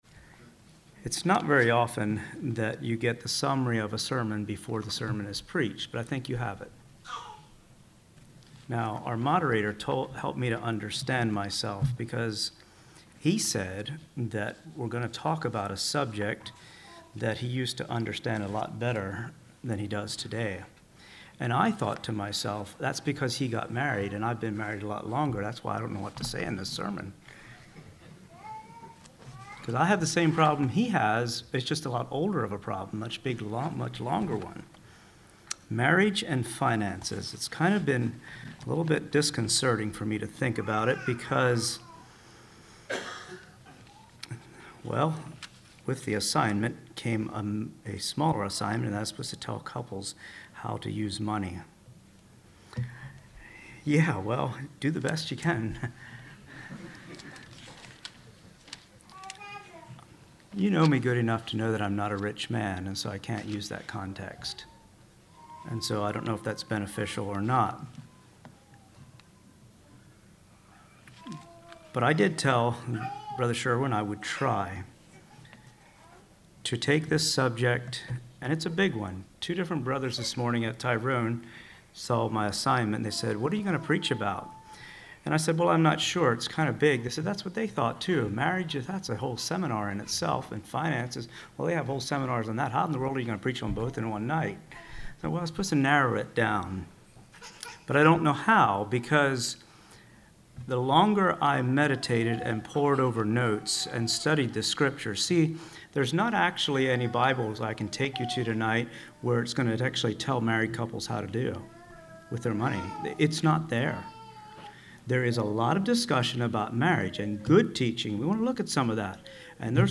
This sermon lays out a foundation and the structure of a properly functioning marriage. A wife's submission and the husband's role in leadership are essential for finances in marriage to work properly. Many practical applications are made in this sermon from Jesus' teaching in Matthew 6:19-24.